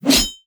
"Metal Hit Whoosh" From Mixkit